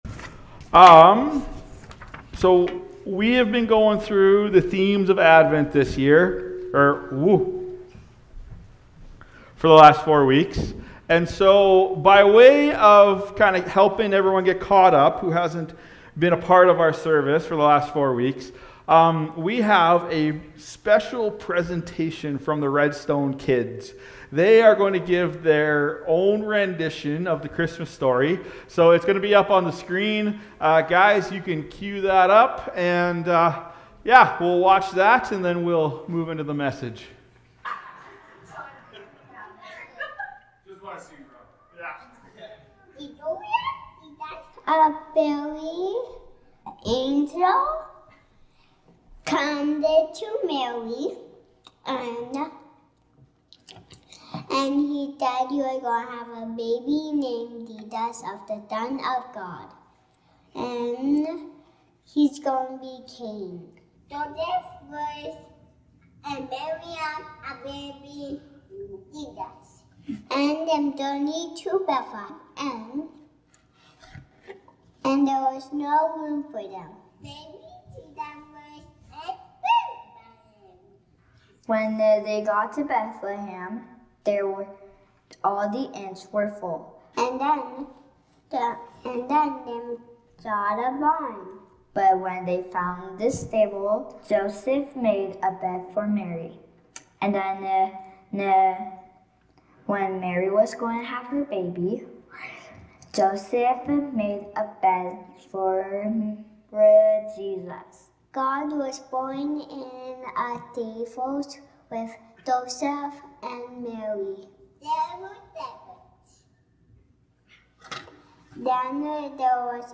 Christmas Eve Service 2024